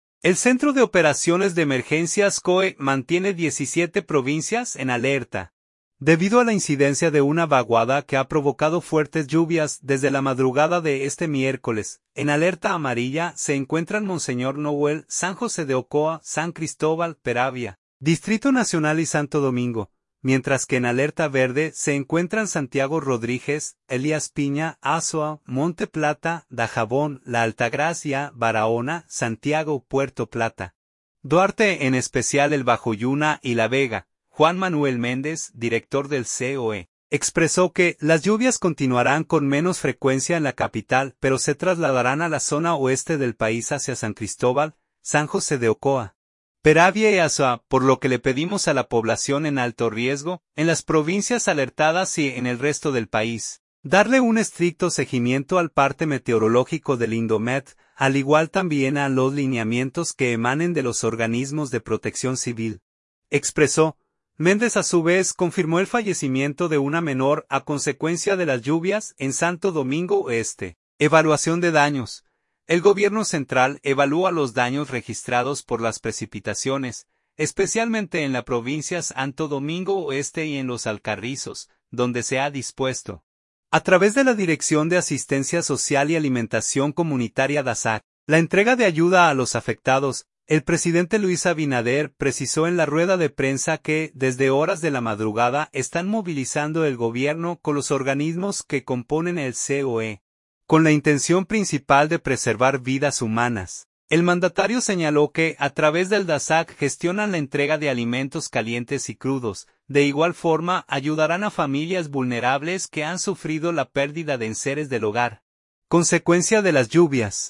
Abinader habla en el COE sobre asistencia a afectados por lluvias; confirman una niña fallecida
El presidente Luis Abinader, precisó en la rueda de prensa que desde horas de la madrugada están movilizando el gobierno, con los organismos que componen el COE, con la intención principal de preservar vidas humanas.